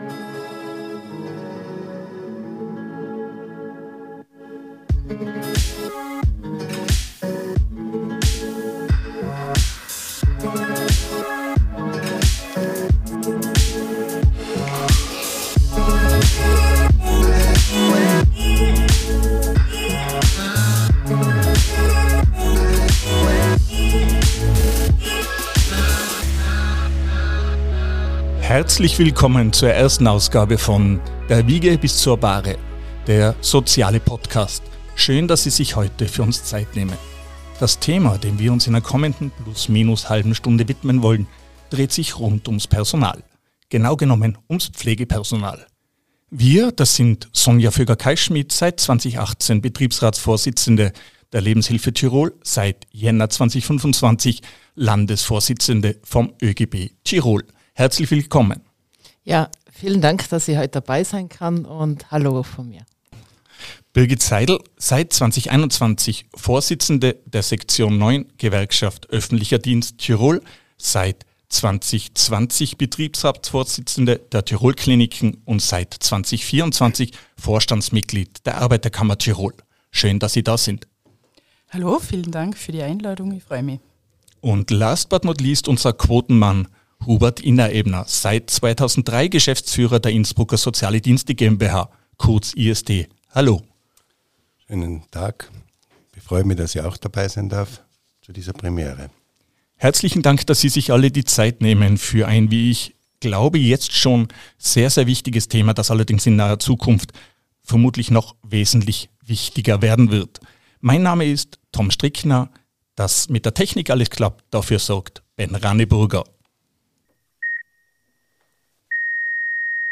im kontroversiellen Austausch über Personalmangel, Bezahlung, Arbeitszeitreduktion und die Pflegelehre. Drei Persönlichkeiten mit mitunter drei Meinungen - und ein Problem.